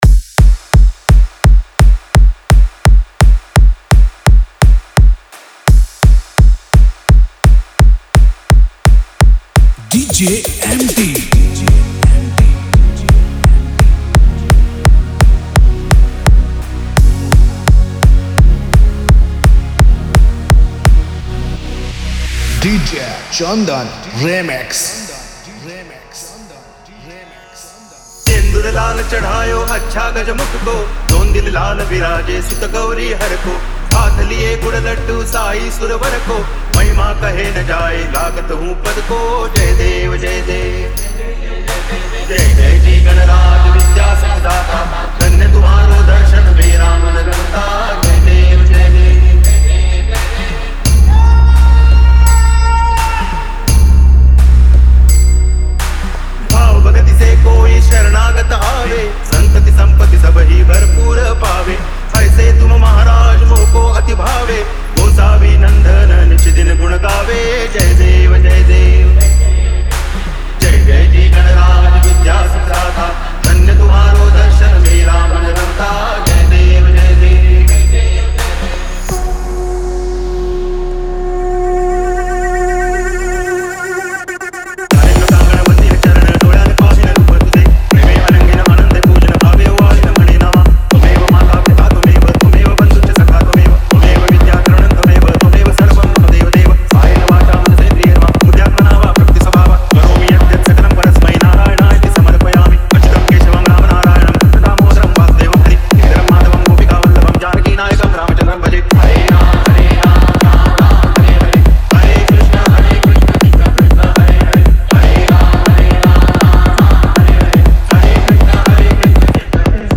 Bhajan Dj Song Collection 2023